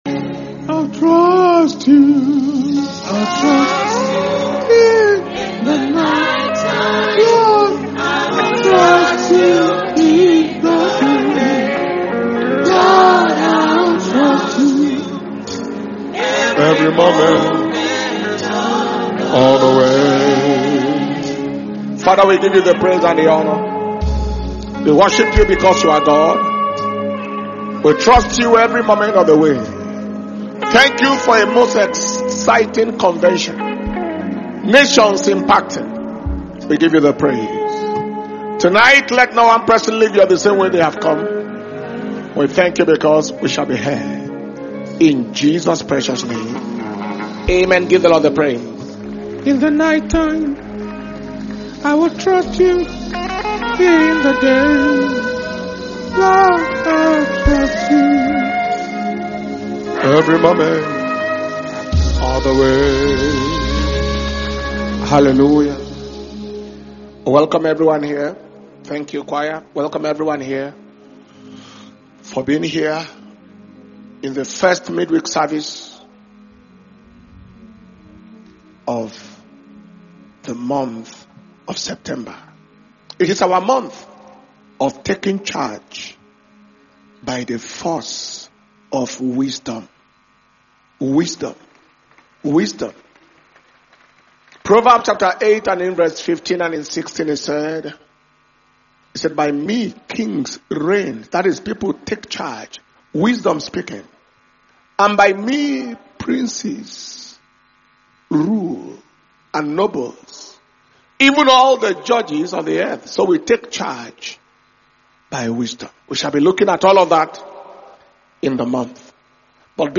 Preservation And Power Communion Service - Wednesday 3rd September 2025